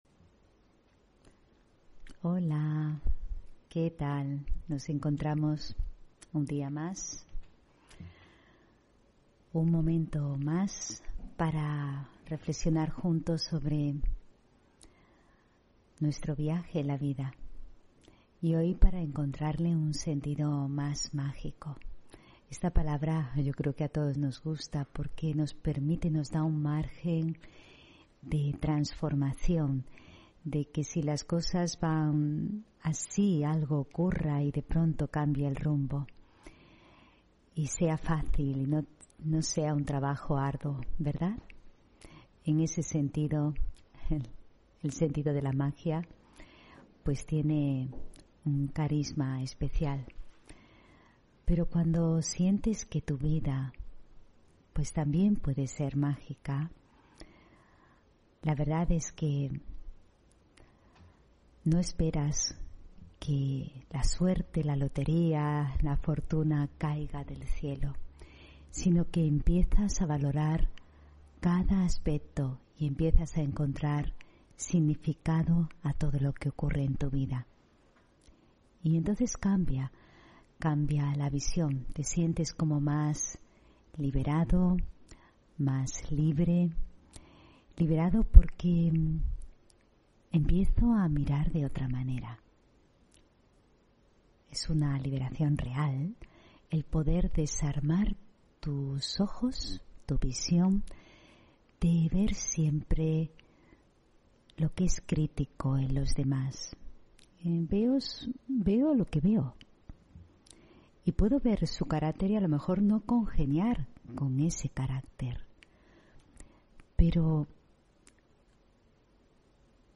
Meditación y conferencia: Encuentra el sentido mágico de tu vida (12 Enero 2022)